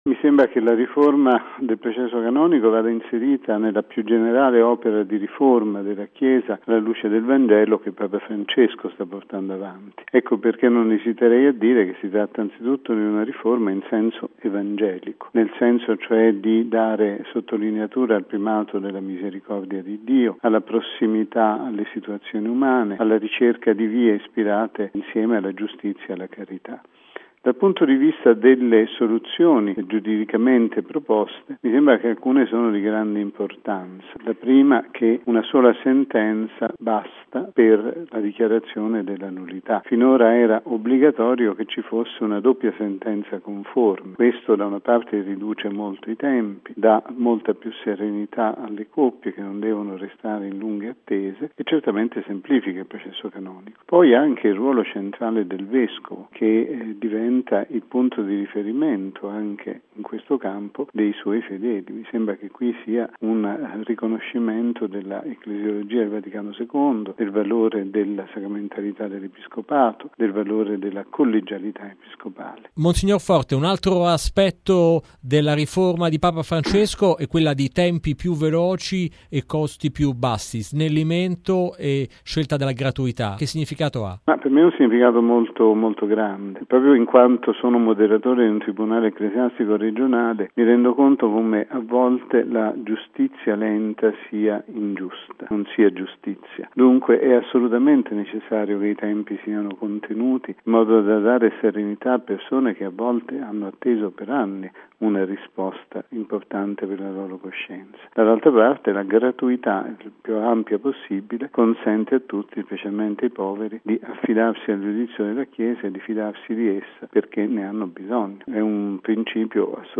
Intervista con Martin Schulz